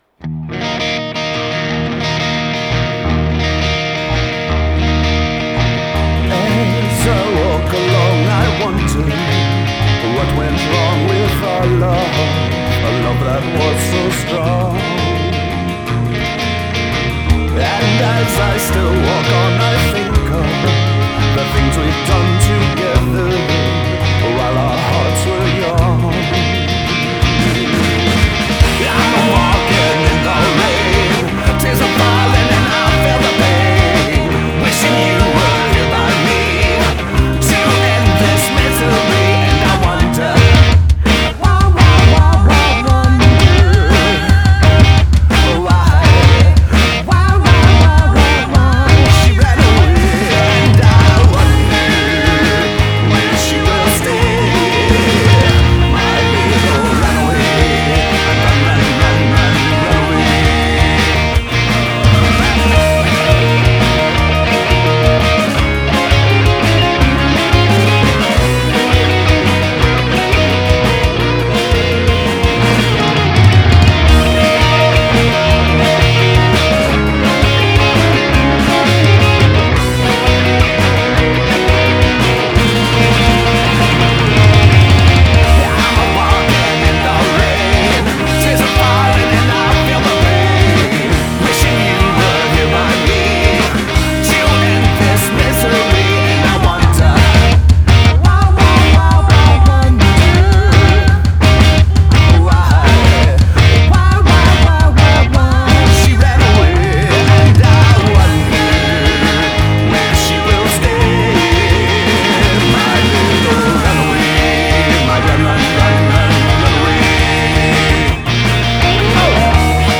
guitar + vocals
bass + vocals
drums